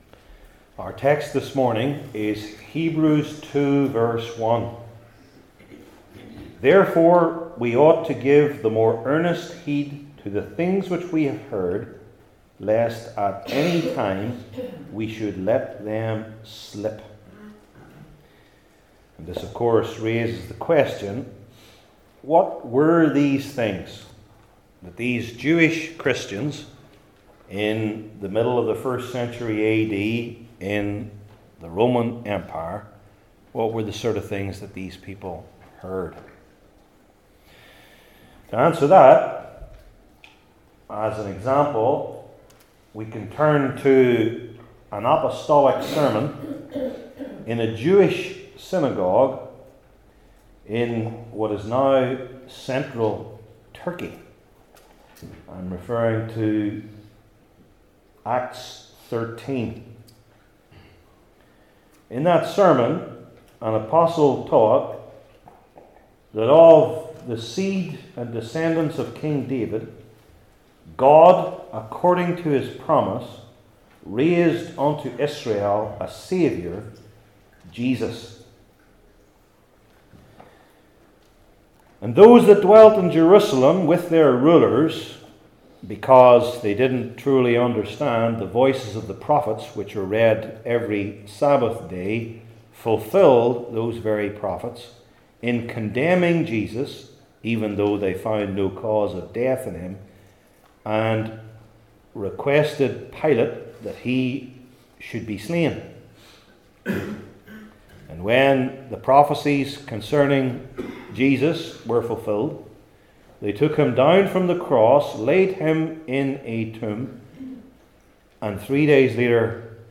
Hebrews 2:1 Service Type: New Testament Sermon Series I. The Vivid Prohibition II.